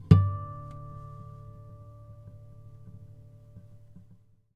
harmonic-06.wav